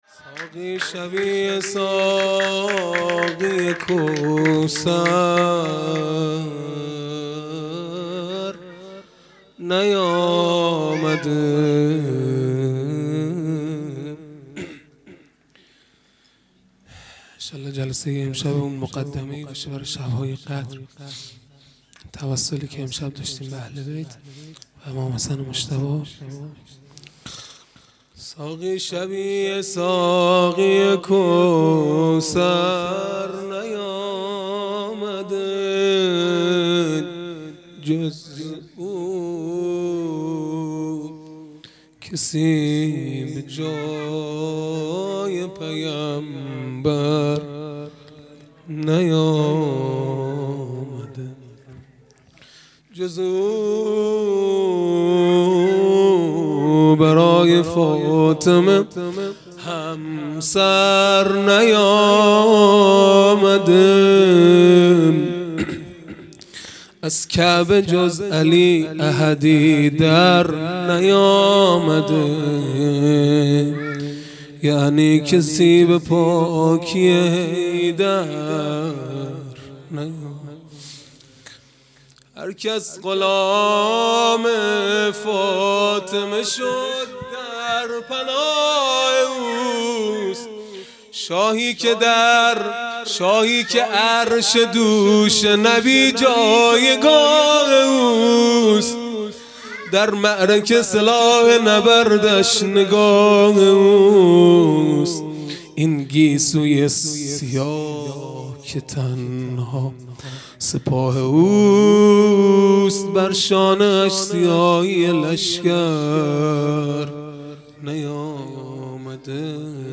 خیمه گاه - هیئت بچه های فاطمه (س) - مدح | ساقی شبیه ساقی کوثر نیامده
جلسۀ هفتگی به مناسبت میلاد امام حسن مجتبی(ع)